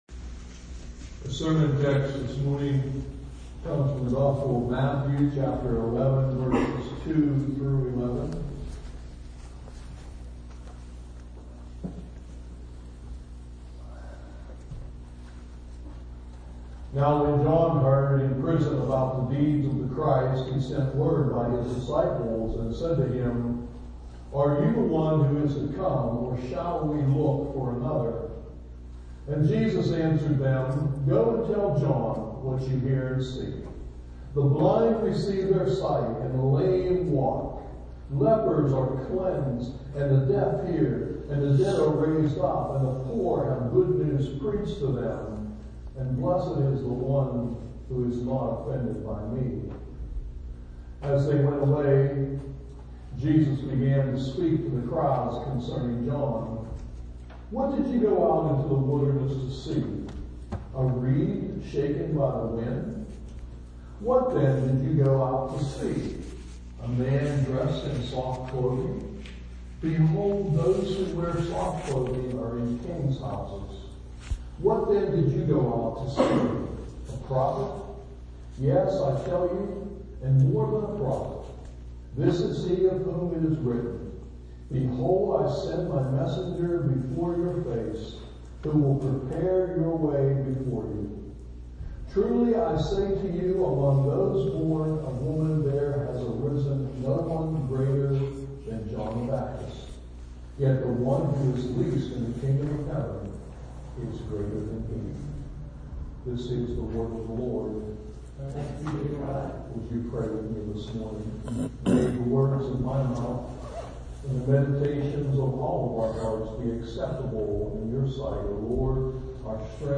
SERMON TEXT: Matthew 11:2-11